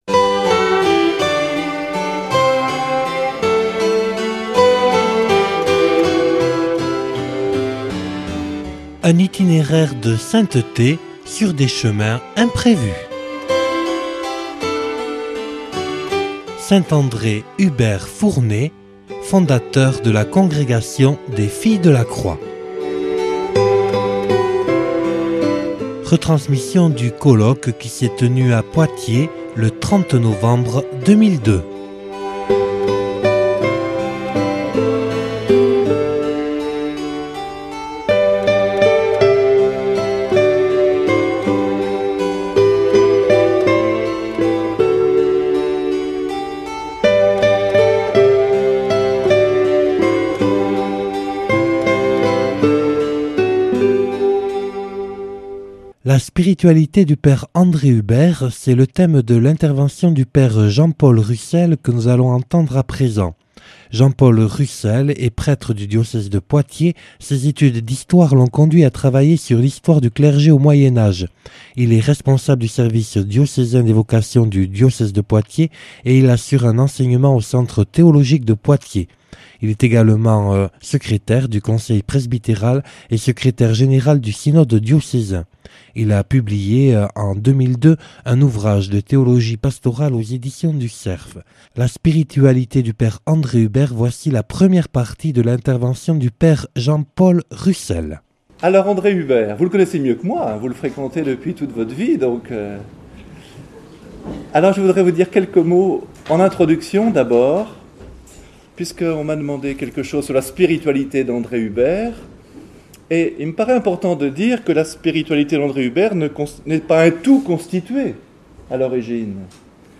(Colloque enregistré le 30/11/2002 à Poitiers).